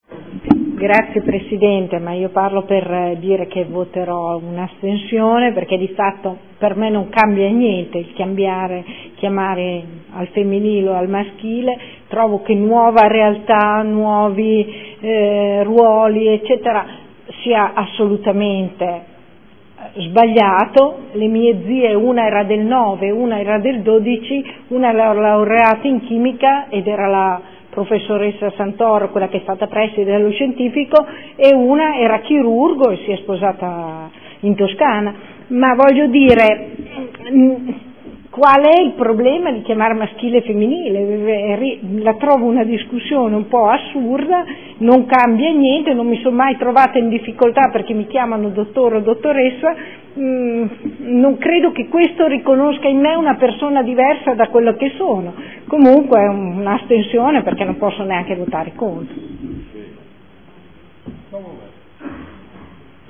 Luigia Santoro — Sito Audio Consiglio Comunale
Seduta del 05/02/2015 Dichiarazione di voto. Atto di indirizzo per l’uso del genere nel linguaggio amministrativo